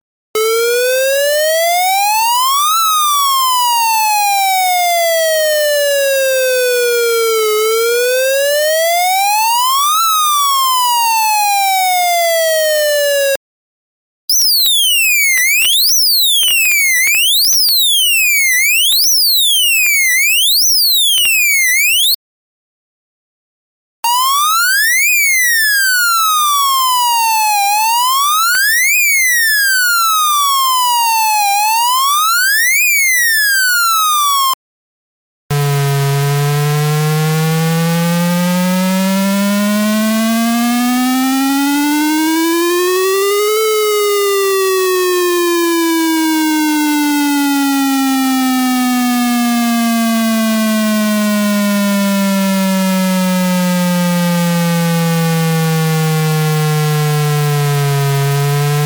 「９５６１基板」の音色をスイッチで変更して、１６通りのサウンドを発生することのできる基板です。
上記サンプル音は下記Ｎｏ．８〜Ｎｏ．１１の順番で再生されます。
消防車
ホイッスル
警報音
サイレン４